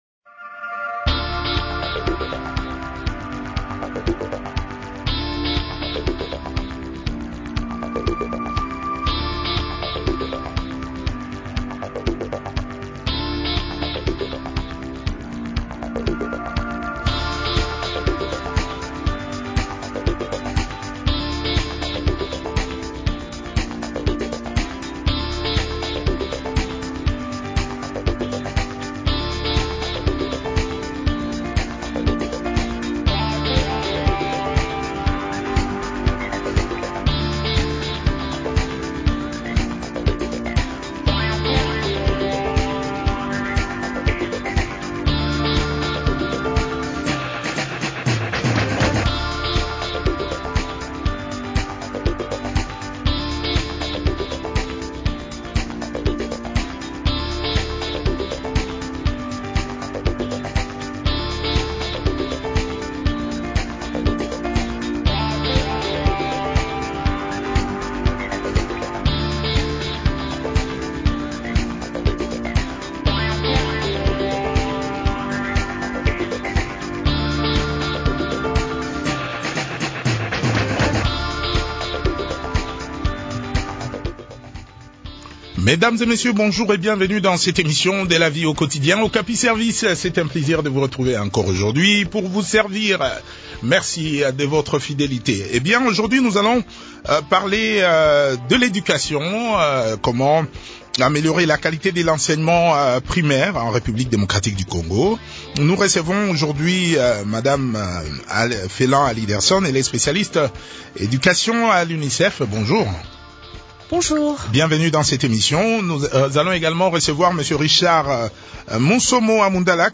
a également participé à cet entretien.